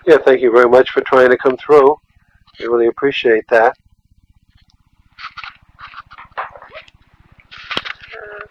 All of the investigations were conducted between 12 midnight and 3am over a two day period.
EVP's